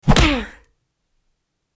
Âm thanh Cú Đấm Ừừừ... hạ gục kẻ thù
Tiếng Cú Đấm bịch (phim võ thuật) Tiếng Cú Đánh hạ gục kẻ thù Aaa….
Thể loại: Đánh nhau, vũ khí
am-thanh-cu-dam-uuu-ha-guc-ke-thu-www_tiengdong_com.mp3